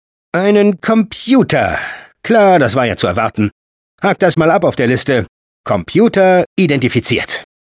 File File history File usage Wheatley_sp_a2_bts5_hack06_de.wav  (file size: 75 KB, MIME type: audio/mp3 ) Summary Wheatley German voice files Licensing This is an audio clip from the game Portal 2 .